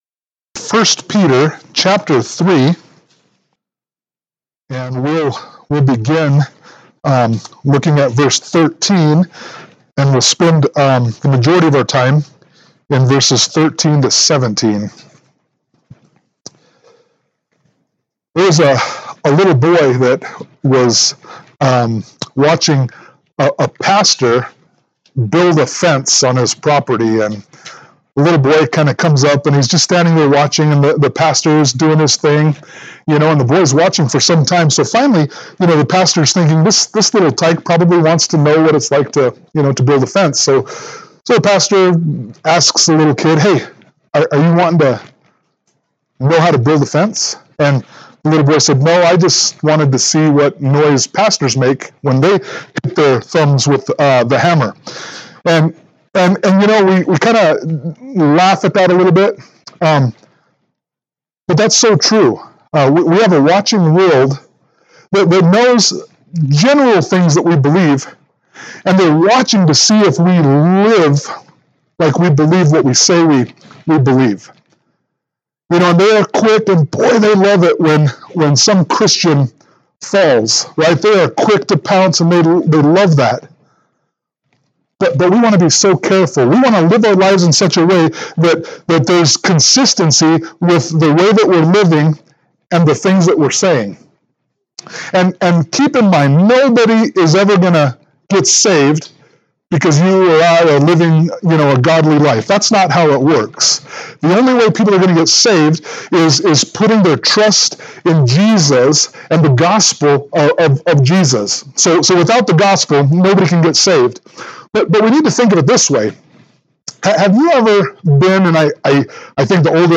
1 Peter 3:13-17 Service Type: Sunday Morning Worship « 1 Peter 3:8-12